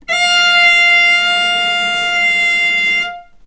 vc-F5-ff.AIF